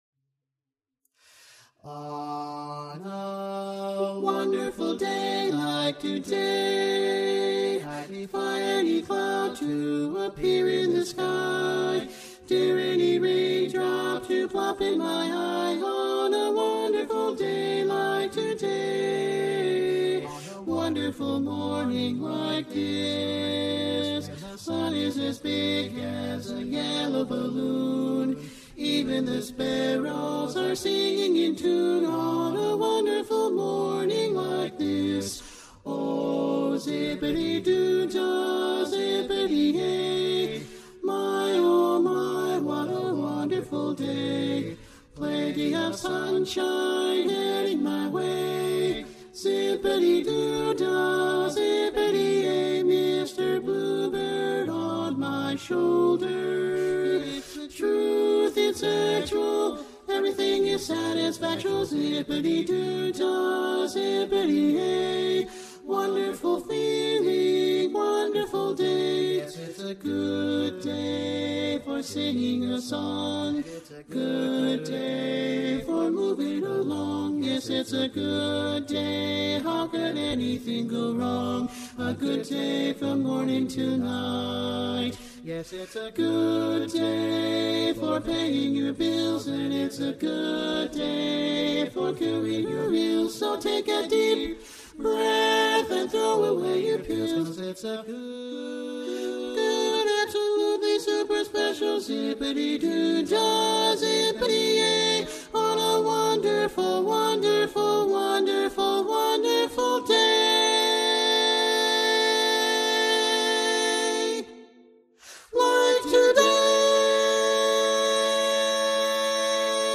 G Major
Tenor